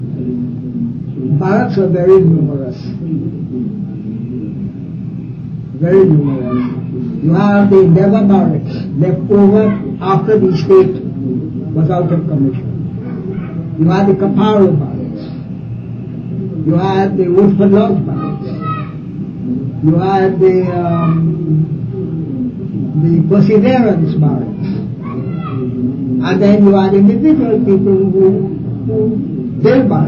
dc.description5 audio cassettesen
dc.typeRecording, oralen